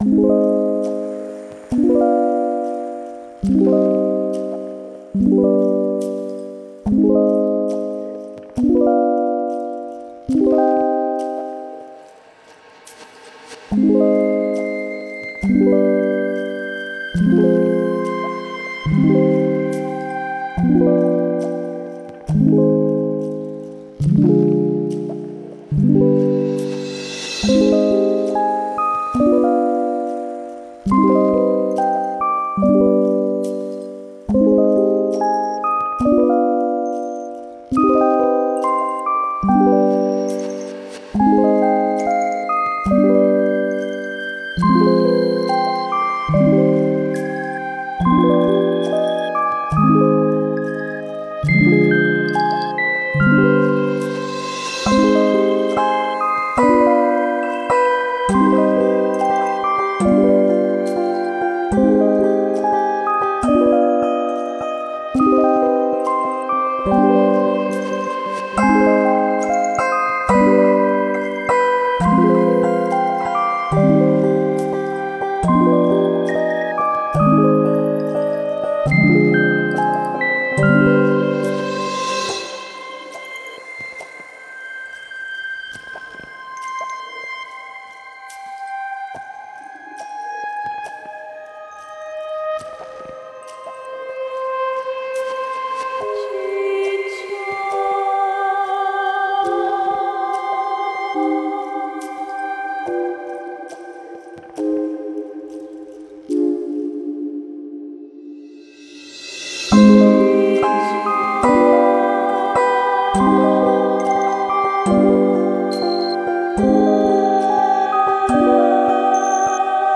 Angelic yet reflective ambience with delicate rhodes lead.